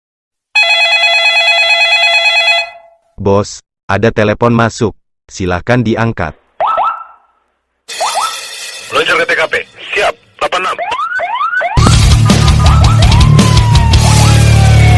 Nada dering Bos ada telepon masuk
Kategori: Nada dering